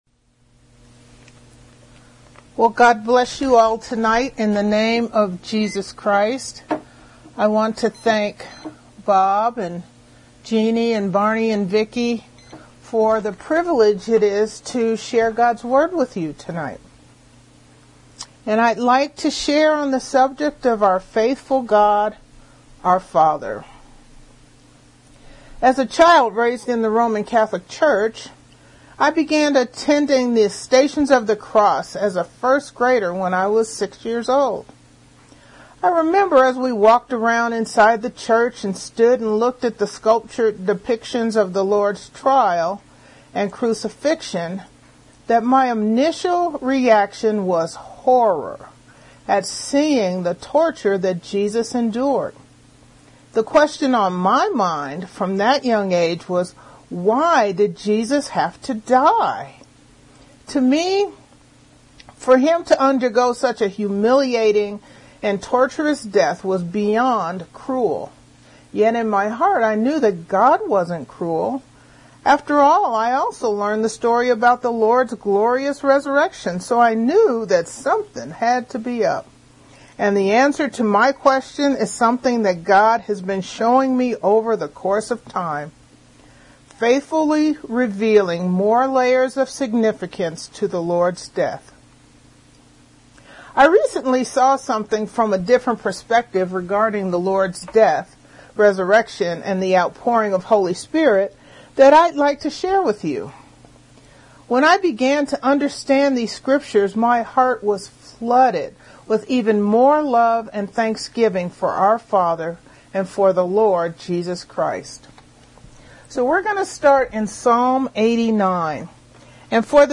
Our Faithful God and Father Details Series: Conference Call Fellowship Date: Thursday, 07 May 2020 Hits: 869 Play the sermon Download Audio ( 3.57 MB )